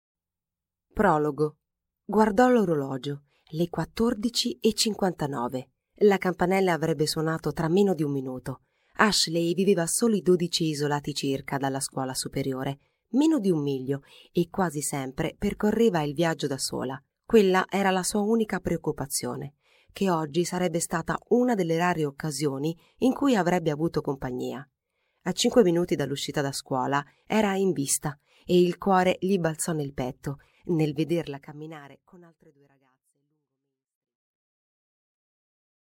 Аудиокнига Tracce di Morte | Библиотека аудиокниг